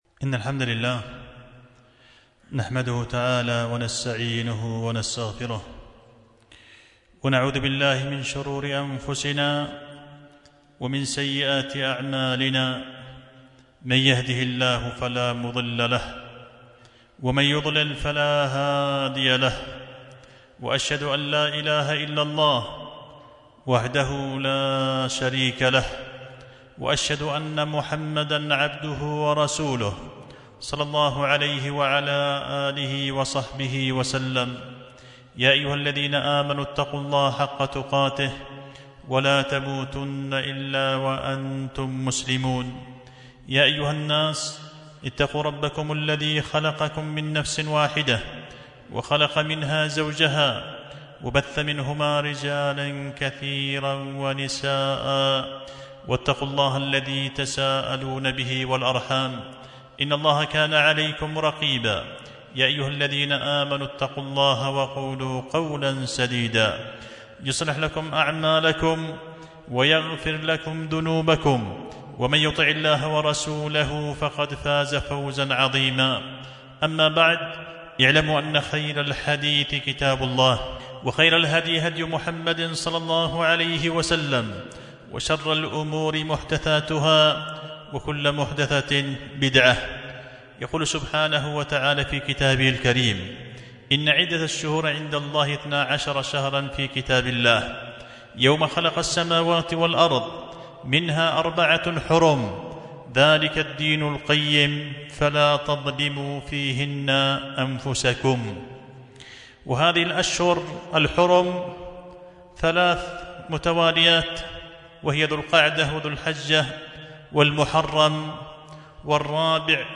خطبة جمعة بعنوان القول المسموع في الاستغناء بالمشروع عن الممنوع